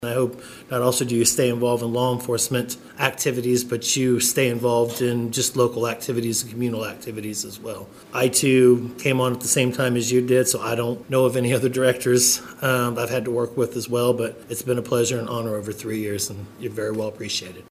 After three years and one month leading the Riley County Police Department, Riley County Police Department Director Dennis Butler sat in on his final Law Board meeting Tuesday afternoon at Manhattan City Hall.